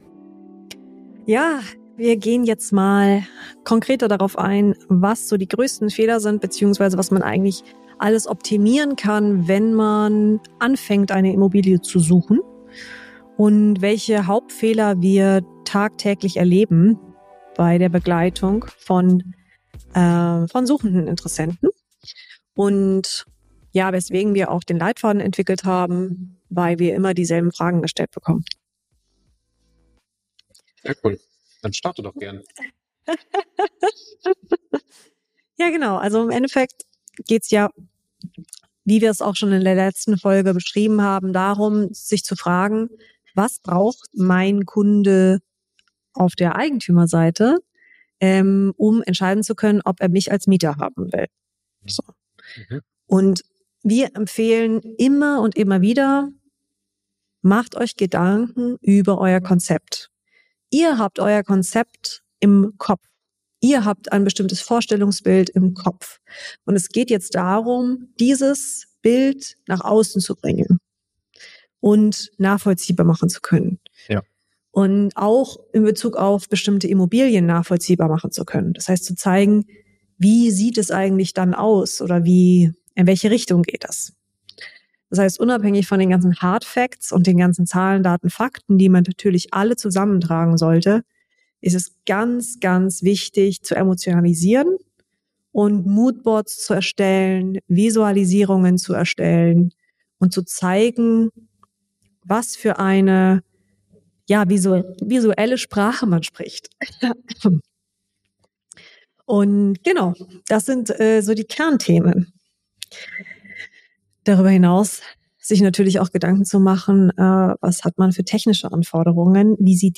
Interview | Warum Kompromisslosigkeit zu einer klaren Vision führt ~ GewerbeRaum: Investieren & Betreiben in München